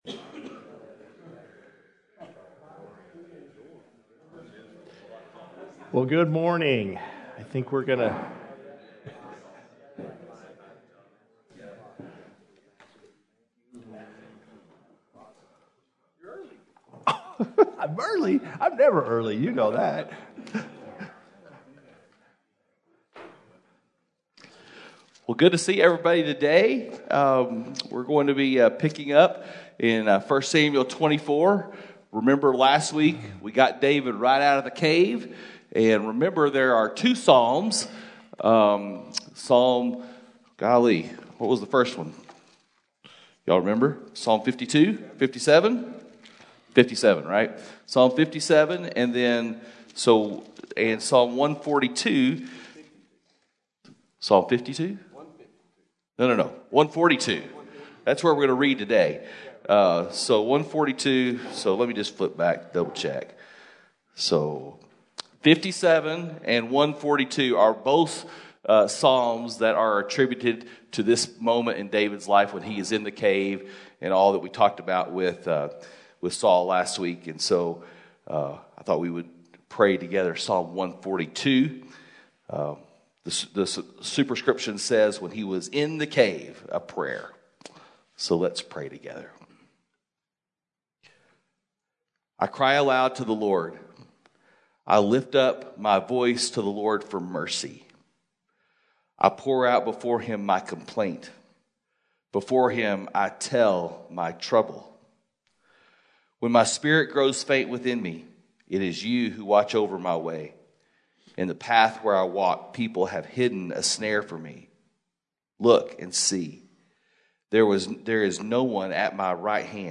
Men’s Breakfast Bible Study 5/25/21